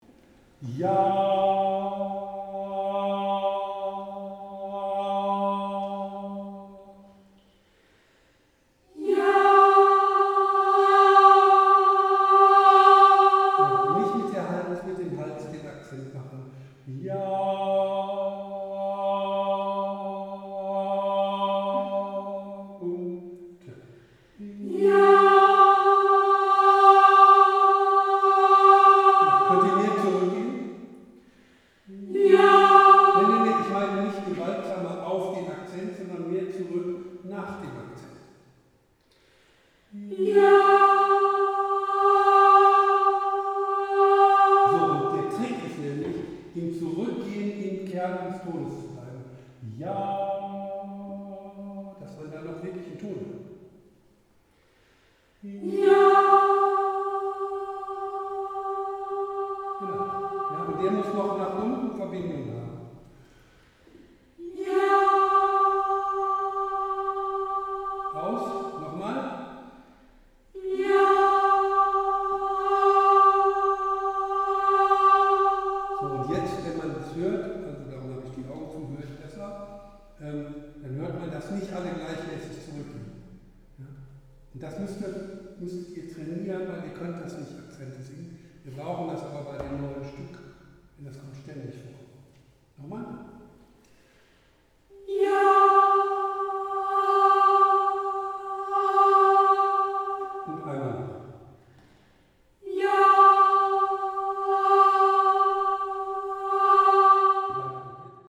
Vorübung Akzente singen – Konzertchor Sång